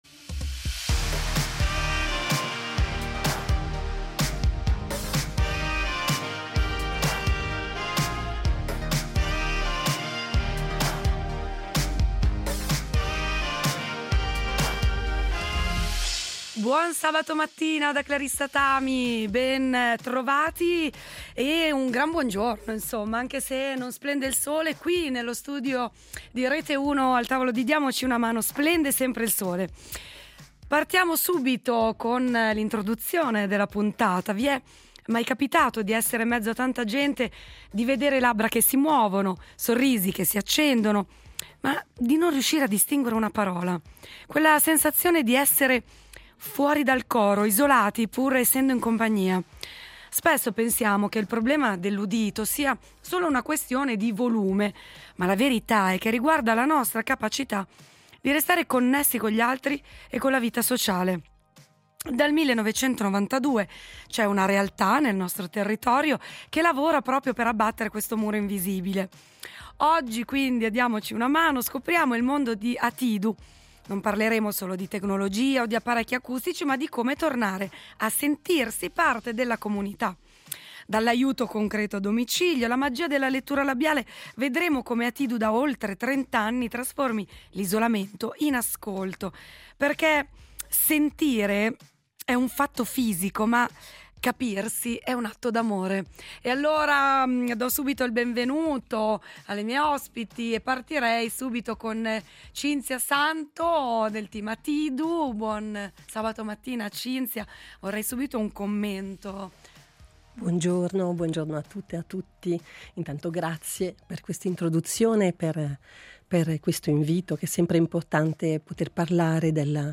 In studio le voci dell’ Associzione ATiDU .